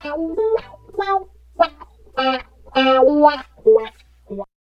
70 GTR 5  -L.wav